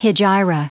Prononciation du mot hegira en anglais (fichier audio)